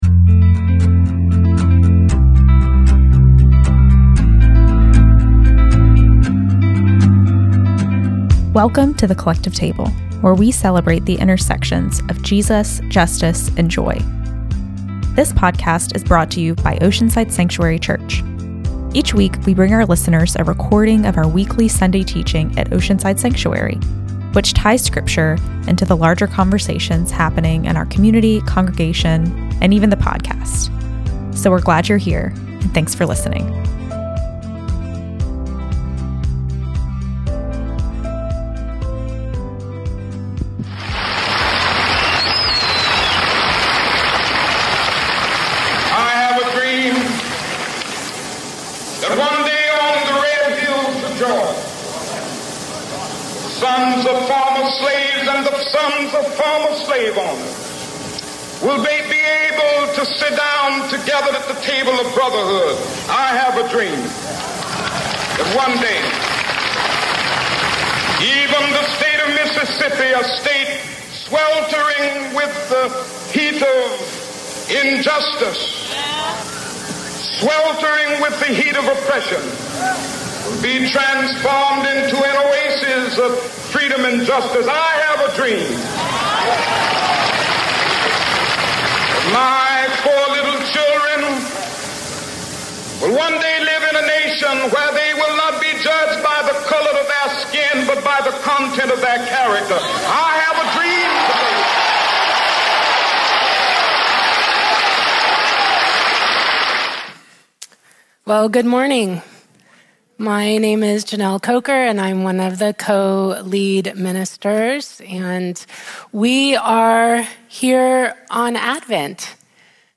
A collection of teachings from our Sunday gathering and classes … continue reading 100 episodes # Society # Religion # Christianity # Podcasting Education # Self-Improvement # Philosophy #